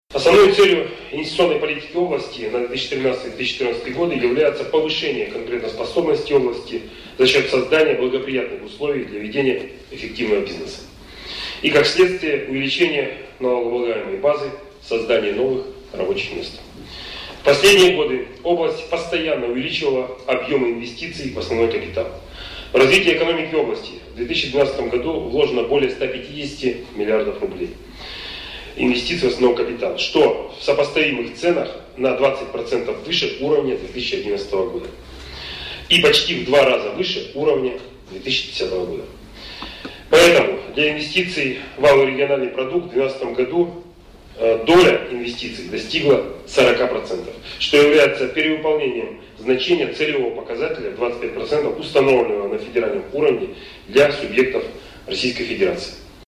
Их рассмотрели 15-го октября на заседании инвестиционного совета при губернаторе области, который был создан для координации деятельности по привлечению средств в регион.